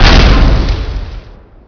explode.wav